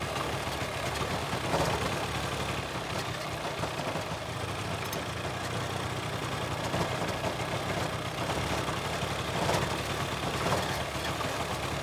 Engine 1 Loop.wav